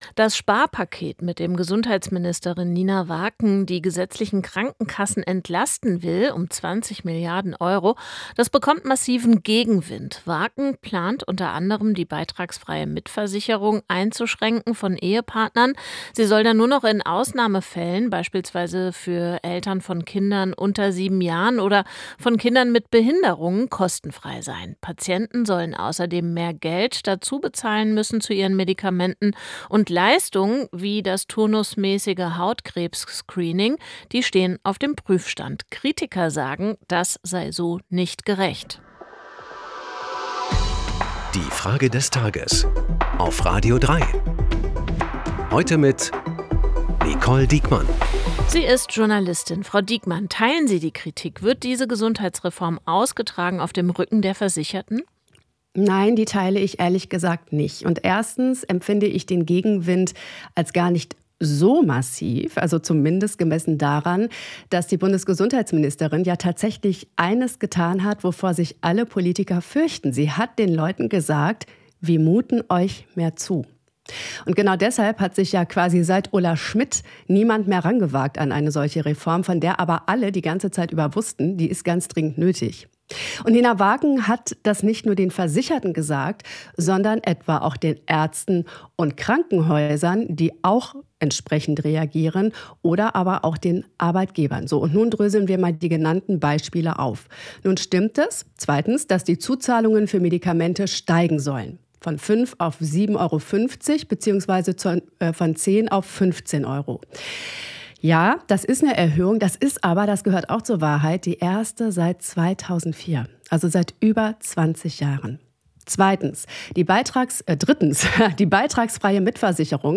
Zehn starke Stimmen im Wechsel "Die Frage des Tages" – montags bis freitags, immer um 8 Uhr 10.
Das ist unsere Frage des Tages an die Journalistin